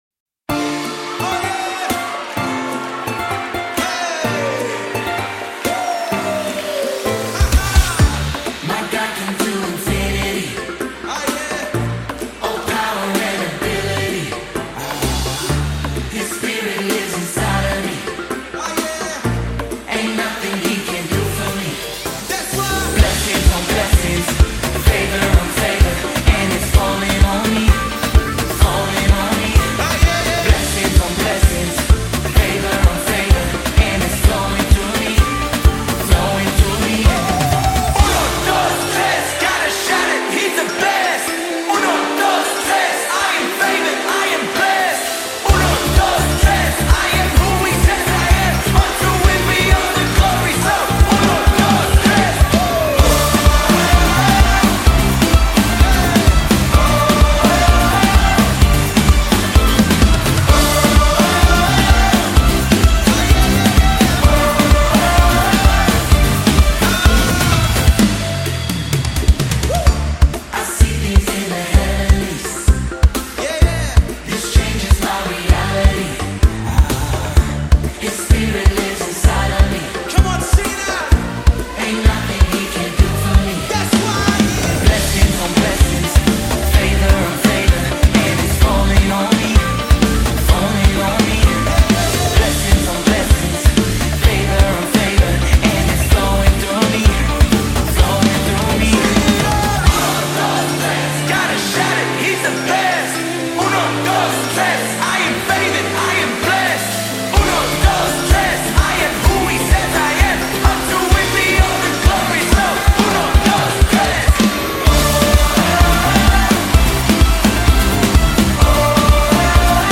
BPM: 128